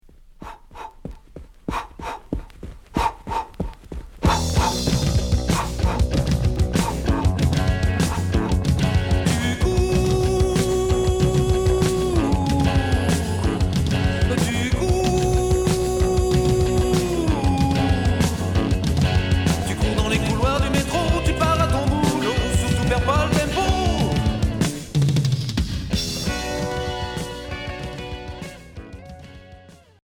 Rock Premier 45t